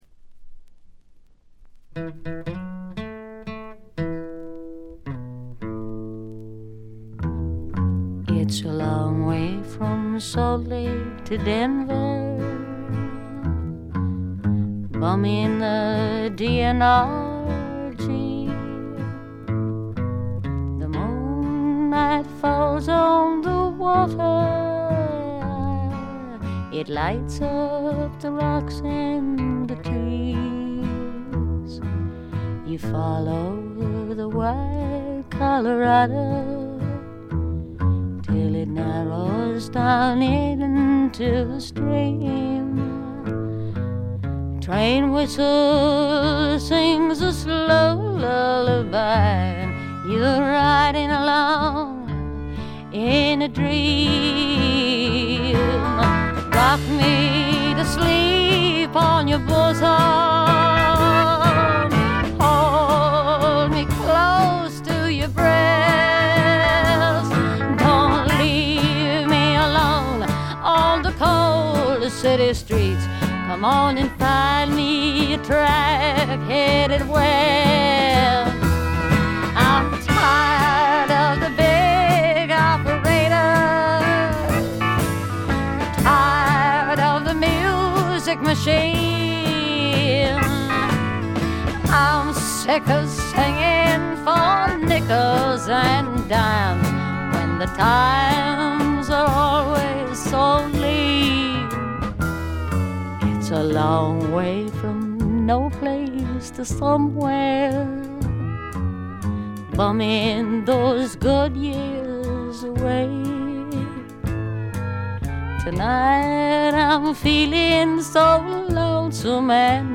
ほとんどノイズ感無し。
試聴曲は現品からの取り込み音源です。
Acoustic Guitar, Electric Guitar